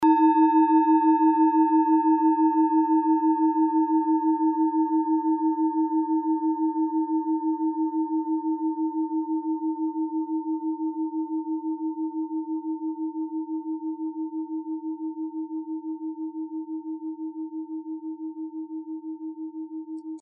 Klangschalen-Typ: Bengalen
Klangschale Nr.7
Gewicht = 560g
Durchmesser = 13,7cm
(Aufgenommen mit dem Filzklöppel/Gummischlegel)
klangschale-set-1-7.mp3